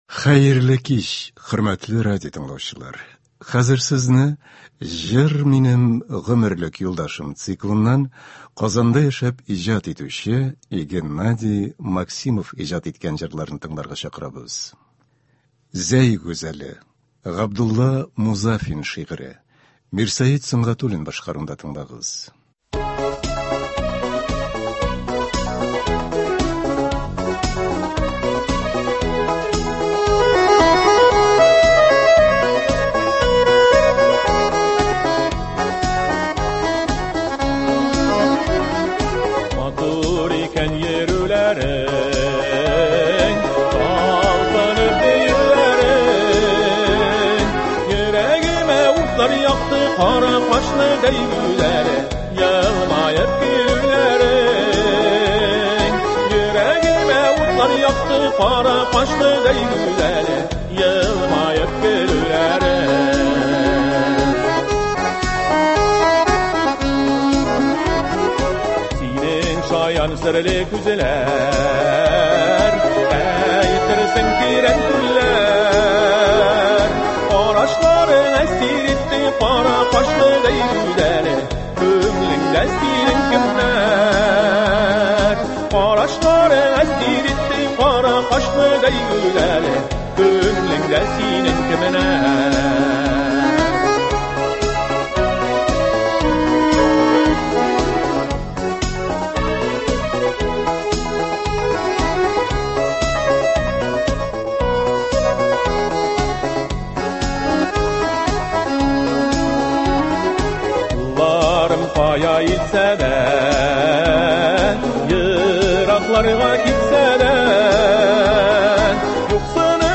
Кичке концерт.